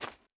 Jump_1.wav